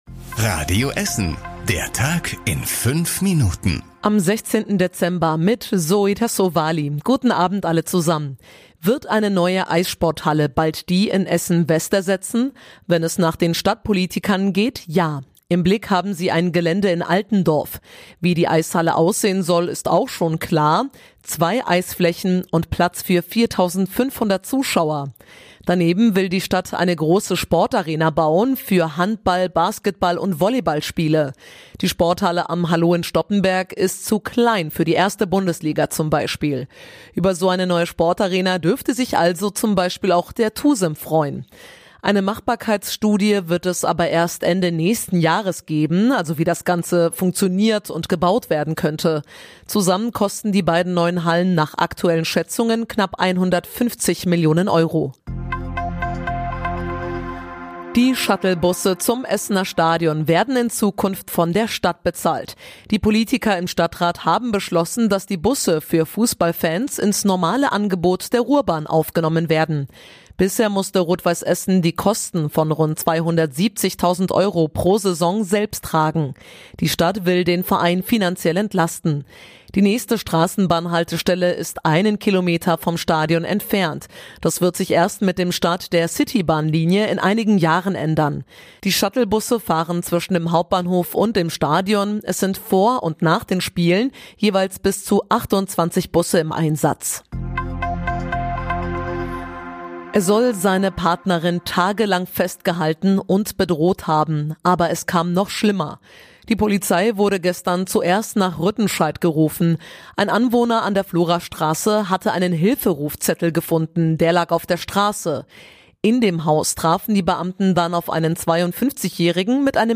Die wichtigsten Nachrichten des Tages in der Zusammenfassung